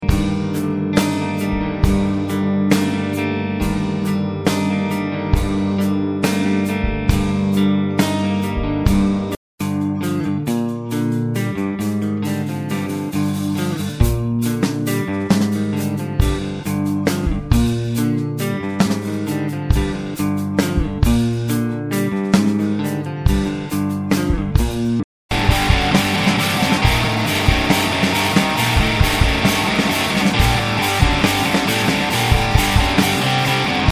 Son de batterie décevant, help me! -
- le son de g.c. est super trop "basses", mais ça ce doit être question de réglages et de batte de pédale.
Dès que c'est pas rim shot, c'est tout mou, et même en rim shot bof...
Conditions d'enregistrement : config de bon home studio (grosse table numérique, cubase, carte son MOTU, configuration classique des micros batterie)
Au final, je trouve le son un poil "clinique", je sais pas trop comment dire...
Trop propre, confiné, moelleux, pas naturel, presque "club" par moments !
Ca sonne pas du tout comme en vrai, vous allez me dire c'est normal vu que les micros sont à 4 cm de la source, mais c'est vraiment pas naturel, ya un truc...